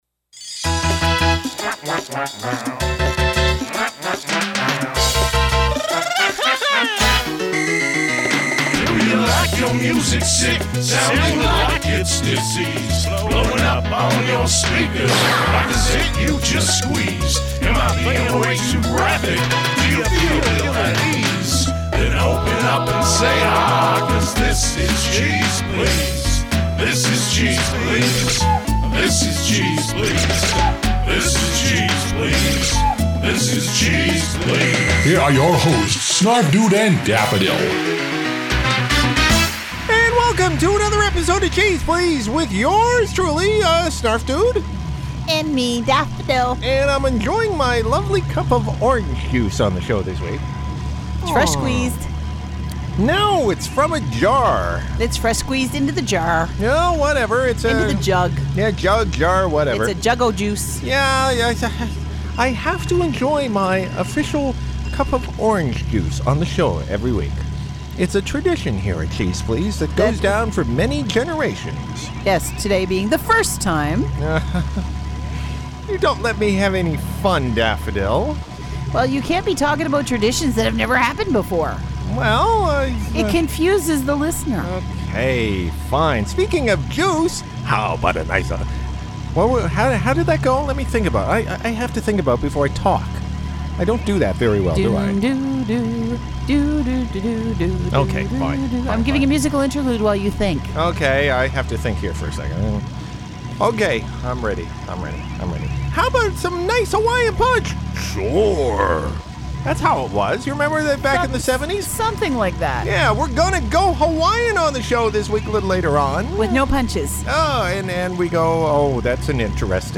Our hosts brave a cold and guzzle down orange juice to bring you a show this week..